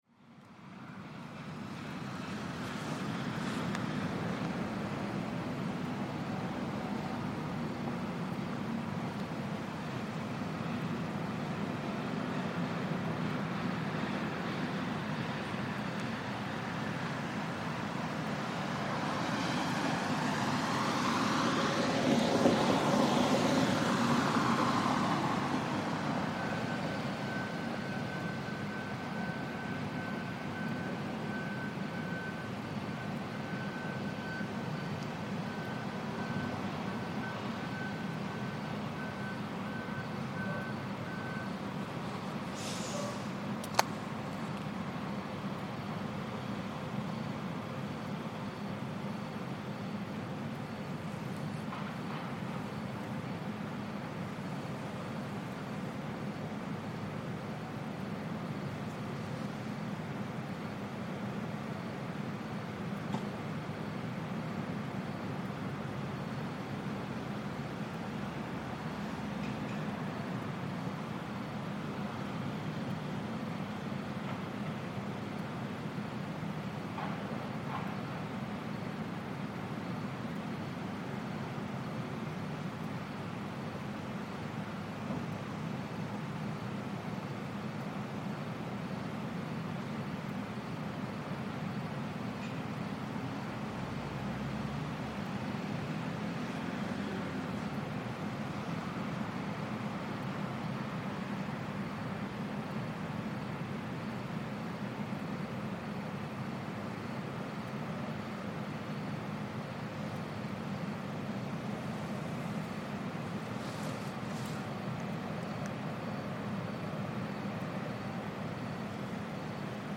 Industrial night time sounds from Limassol harbour in Cyprus - cranes beeping, ferry loading and night traffic in the background.